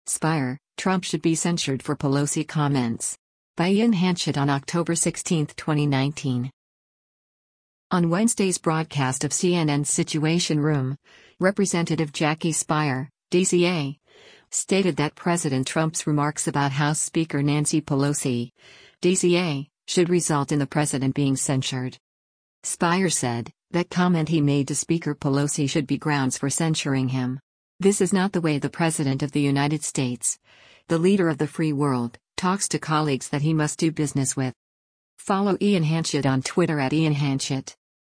On Wednesday’s broadcast of CNN’s “Situation Room,” Representative Jackie Speier (D-CA) stated that President Trump’s remarks about House Speaker Nancy Pelosi (D-CA) should result in the president being censured.